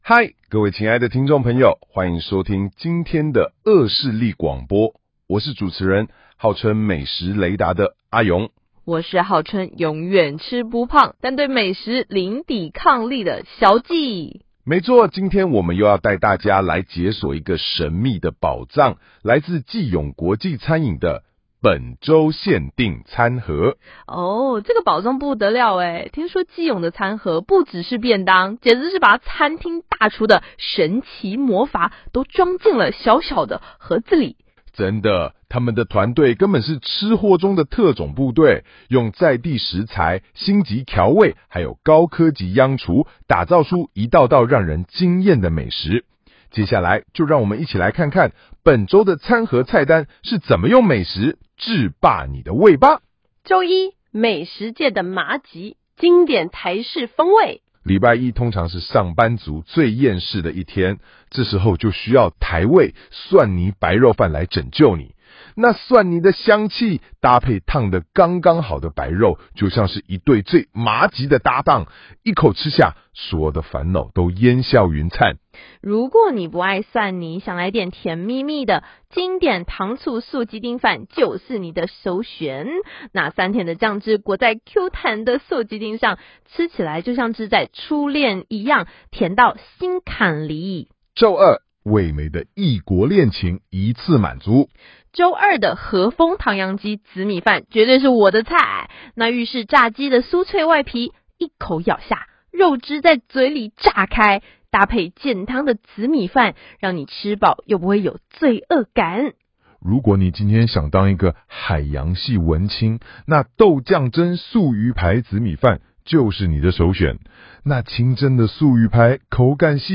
透過 AI 雙人對話 Podcast，生動有趣介紹每週限定餐盒
兩位AI聲音角色化身為「美食主持人」，以對話方式互動介紹菜色特色，讓內容更有趣、更容易被記住。
• A主持人：用專業視角介紹餐盒裡的料理亮點，例如嚴選食材與獨家料理手法。
• B主持人：以顧客角度回應驚喜，補充品嚐心得或趣味插話。
這種有趣的互動方式，讓顧客彷彿在收聽一場輕鬆的廣播節目，美味透過耳朵也能感受，提升了整體品牌體驗。